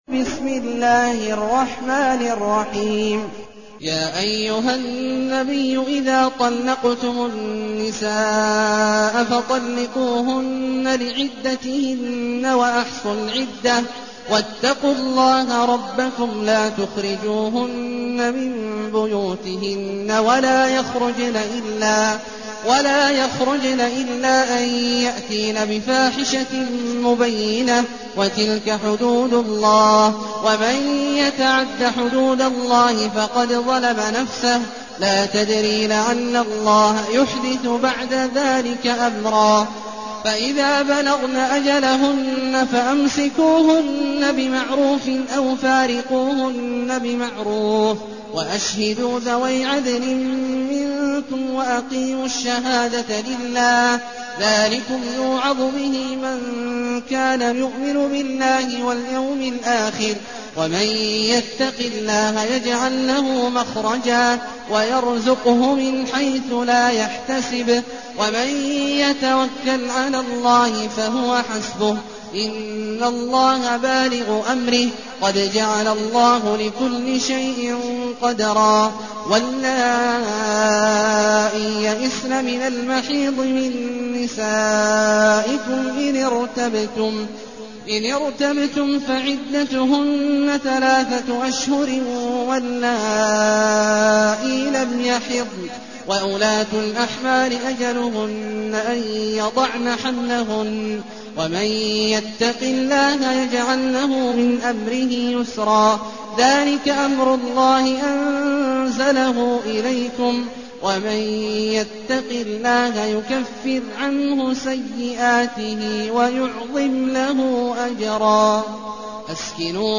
المكان: المسجد النبوي الشيخ: فضيلة الشيخ عبدالله الجهني فضيلة الشيخ عبدالله الجهني الطلاق